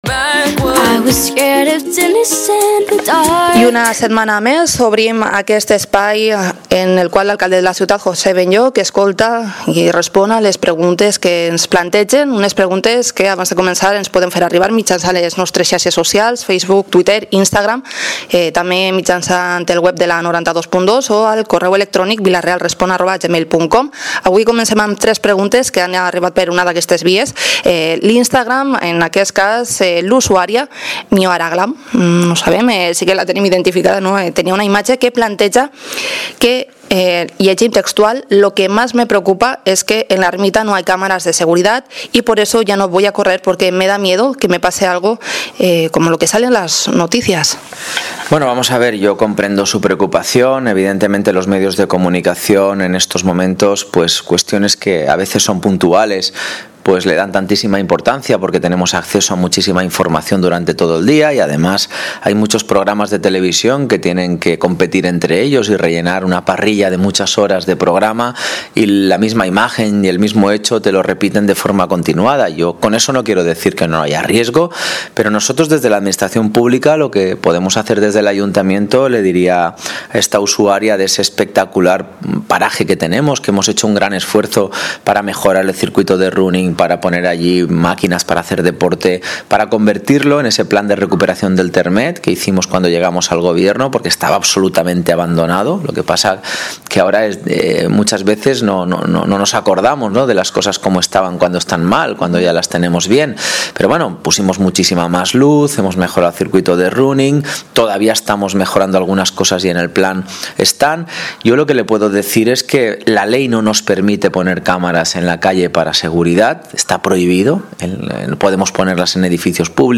Espacio semanal dedicado a preguntas y respuestas al alcalde de Vila-real. Los vecinos plantean sus inquietudes a José Benlloch quien, cada semana, responderá en directo a 10 cuestiones de nuestros oyentes.